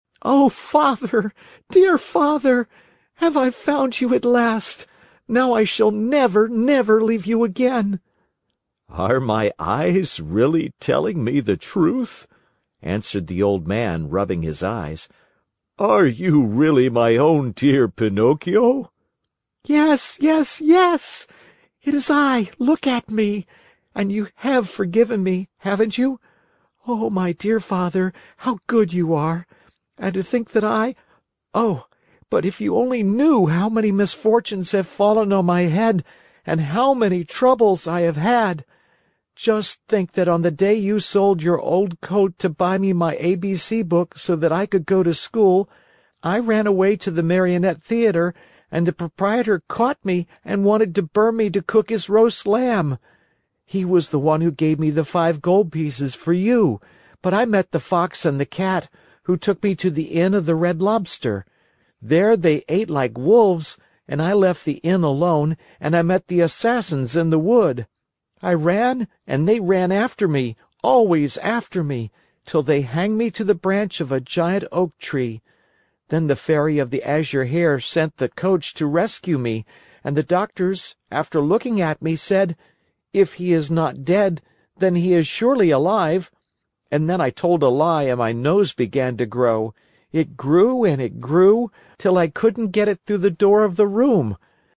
在线英语听力室木偶奇遇记 第149期:鲨鱼腹中的奇遇(2)的听力文件下载,《木偶奇遇记》是双语童话故事的有声读物，包含中英字幕以及英语听力MP3,是听故事学英语的极好素材。